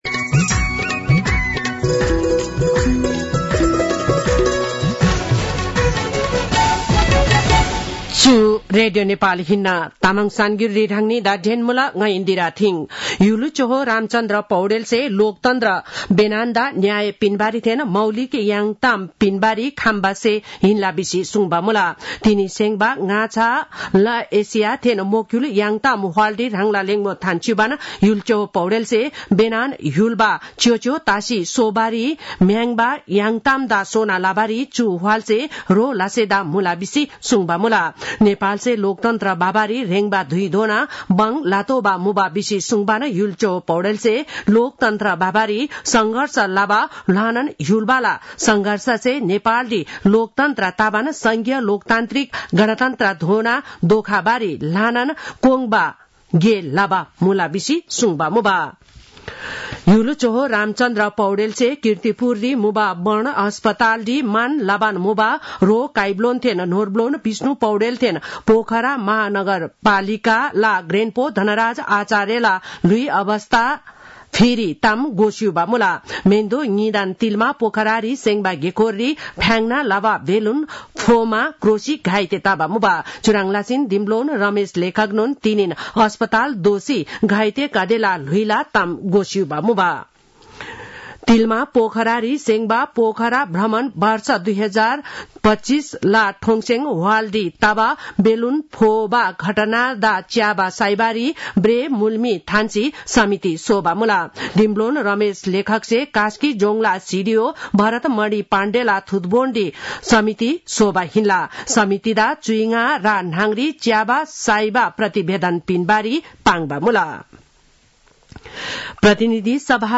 तामाङ भाषाको समाचार : ५ फागुन , २०८१